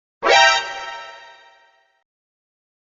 Tags: stream fx